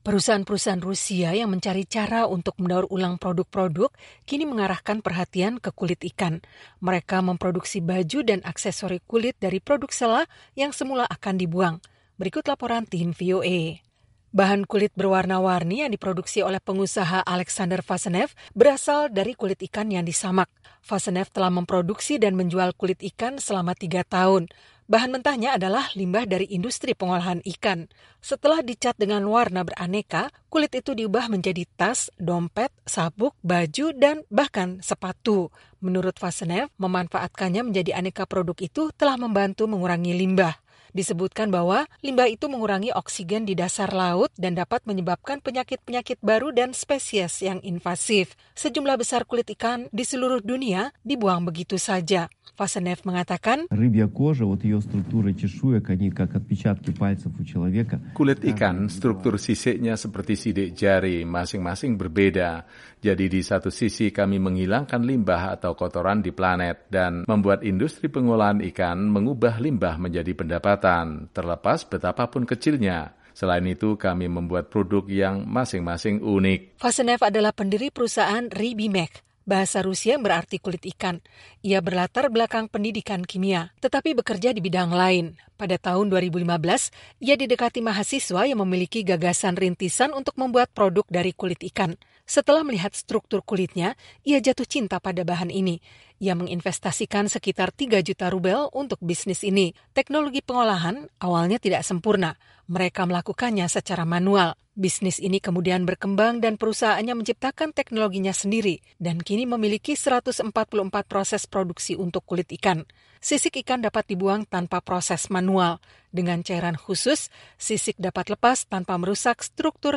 Mereka memproduksi baju dan aksesori kulit dari produk sela yang semula akan dibuang. Berikut laporan Tim VOA.